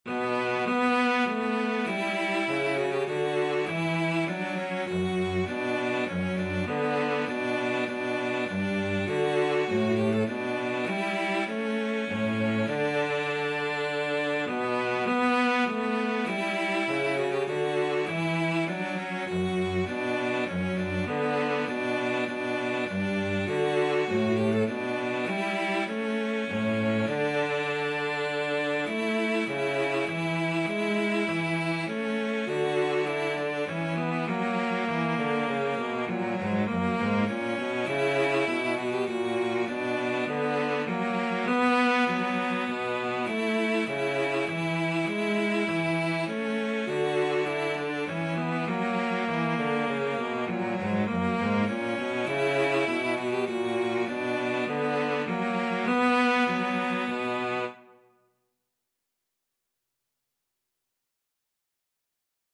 3/4 (View more 3/4 Music)
Cello Duet  (View more Intermediate Cello Duet Music)
Classical (View more Classical Cello Duet Music)